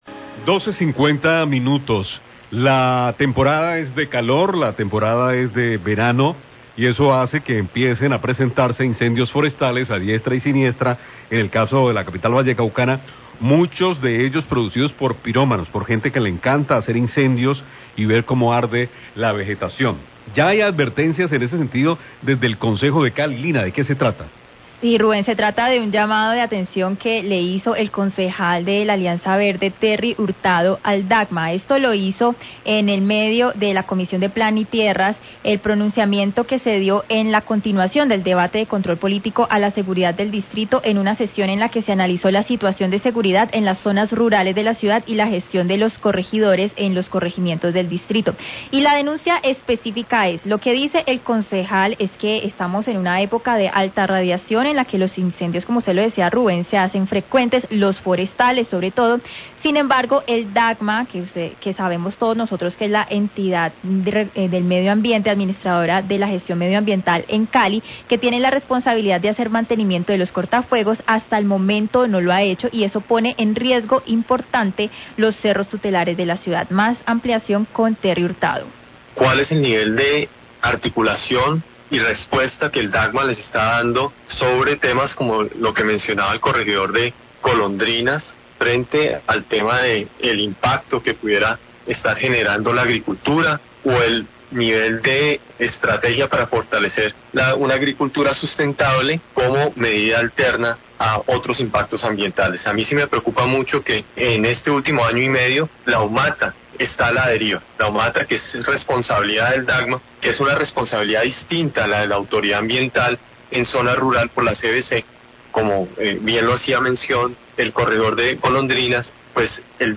Radio
El Concejal Terry Hurtado denuncia, en un asesión de la Comisión de Plan y Tierras, que el Dagma no ha realizado el mantenimiento de los cortafuegos forestales, especialmente en una epoca de altas temperaturas y esto pone en riesgo los cerros tutelares de Cali por los incendios forestales.